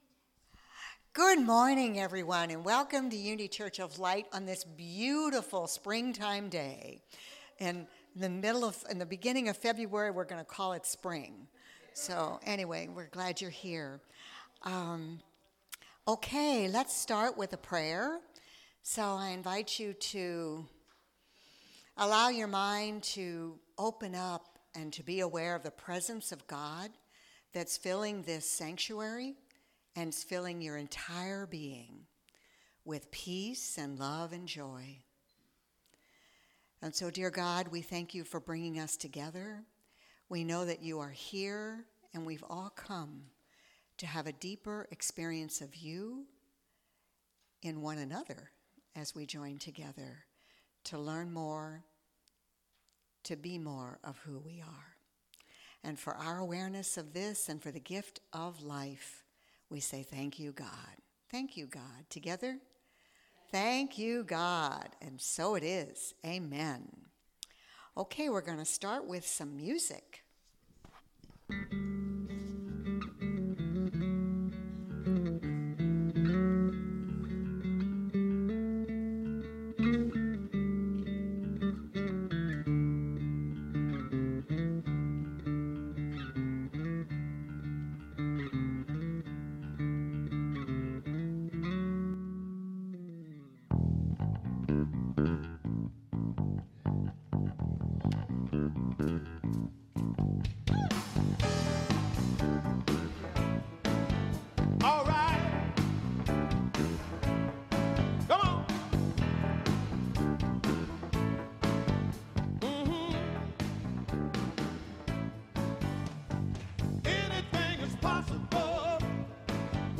Series: Sermons 2023